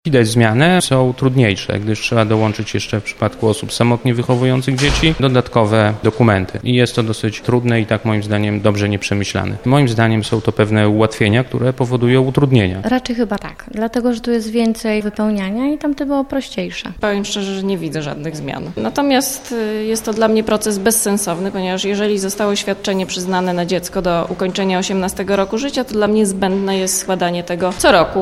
Zapytaliśmy osoby składające nowe wnioski czy zmiany są zauważalne oraz czy ułatwiają one proces: